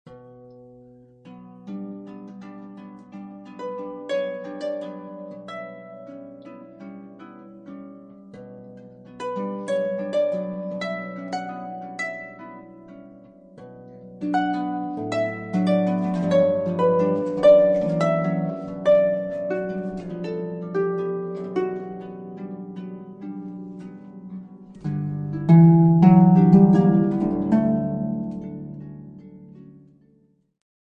an album of superb Russian solo harp music
modern concert harp